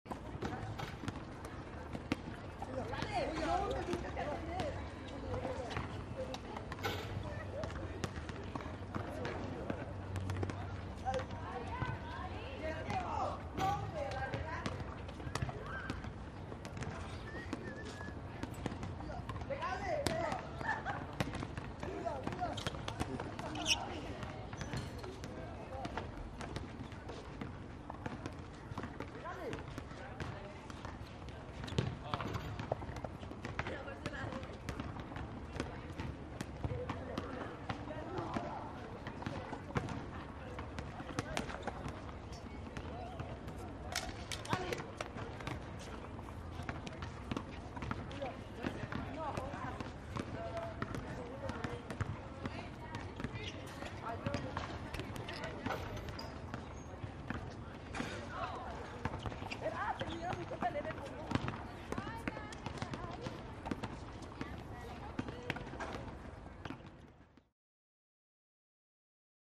High School Playground | Sneak On The Lot
High School Students Play Tennis And Basketball Outdoors